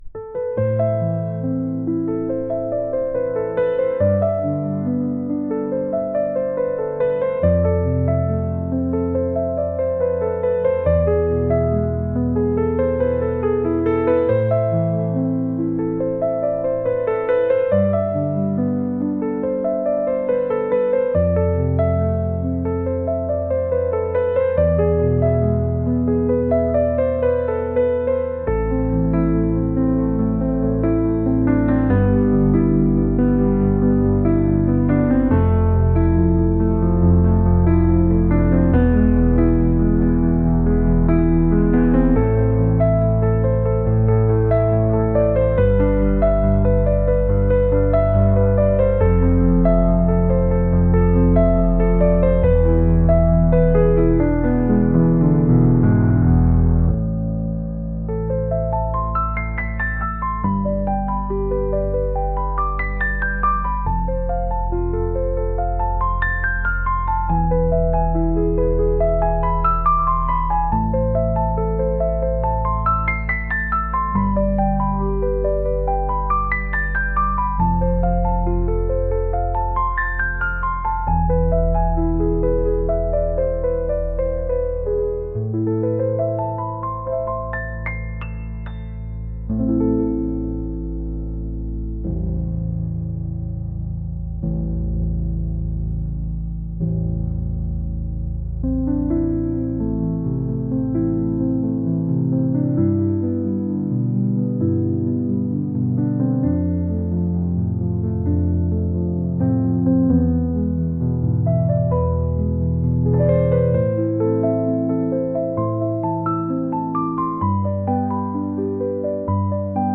ちょっと切ない重ためのピアノ曲です。